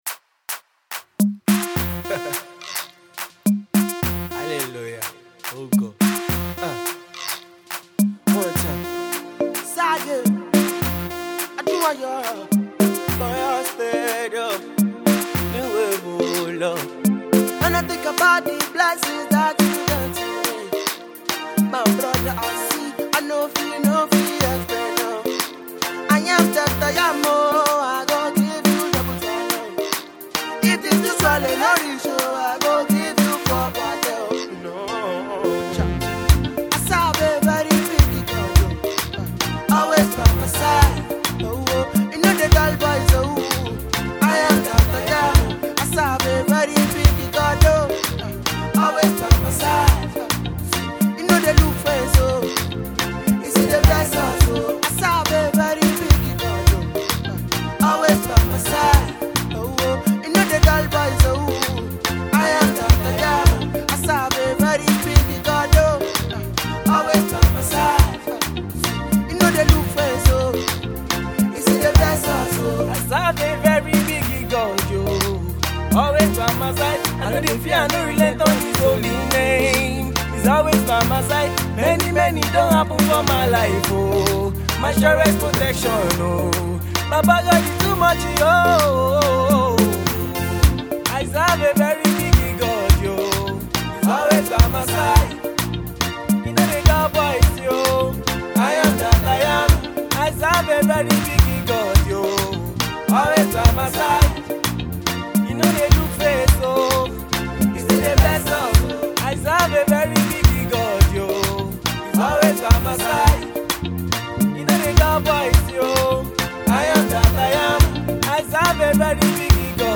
lovely and groovy tune
mix of Afro-Pop and alternative sound